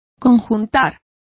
Complete with pronunciation of the translation of coordinating.